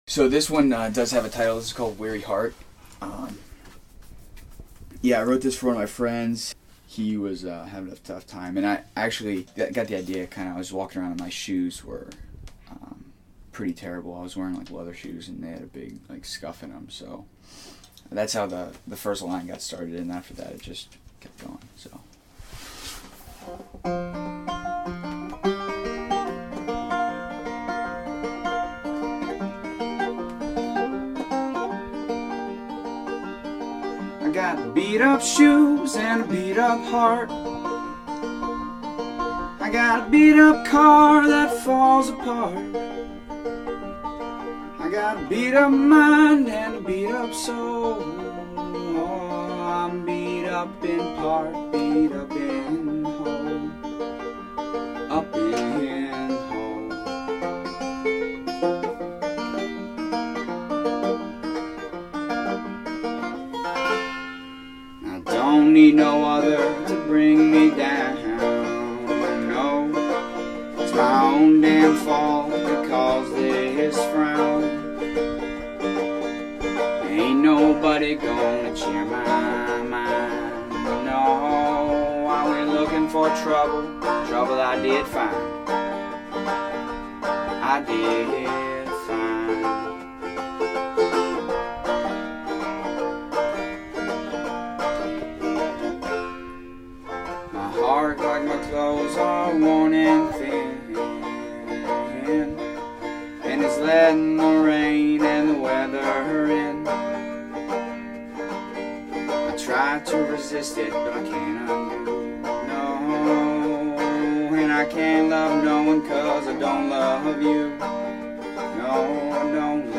He graciously agreed and a week later we sat down for an hour’s worth of conversation.
After all it was my first musical recording session on the lovable but creeky and echoey sailboat that I call my home.